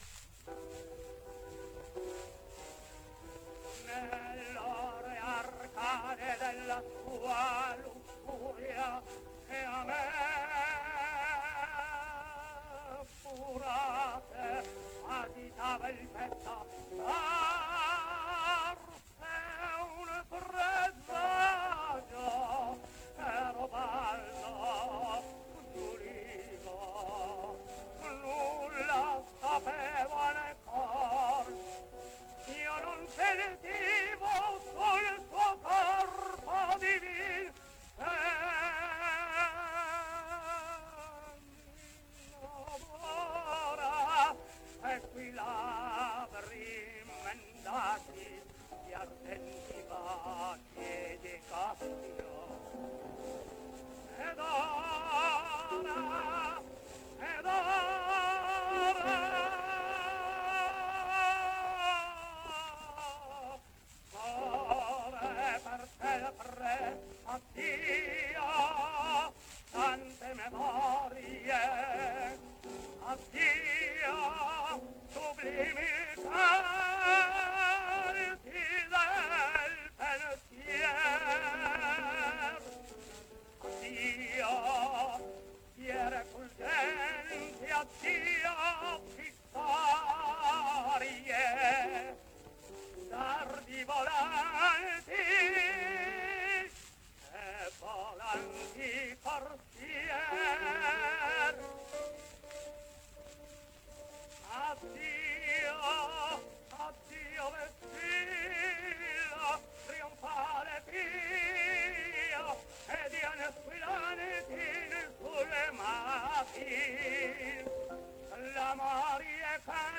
Giuseppe Oxilia sings Otello: